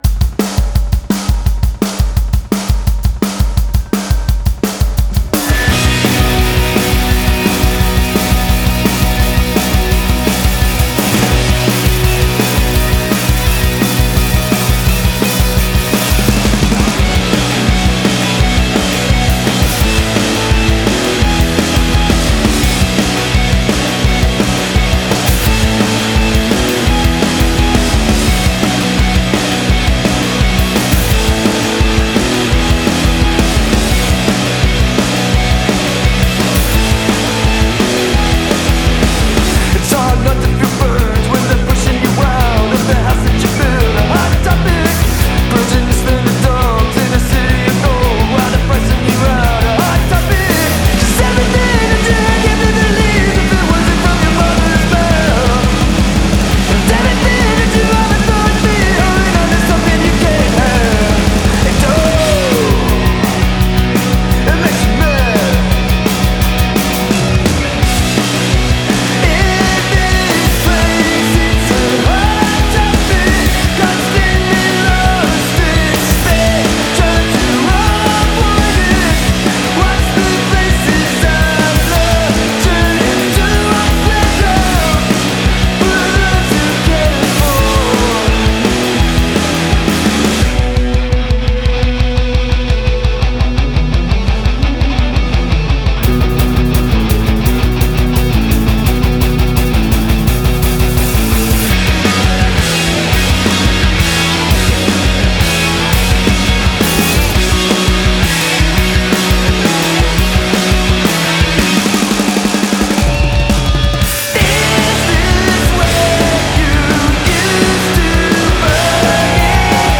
rock hard with tight playing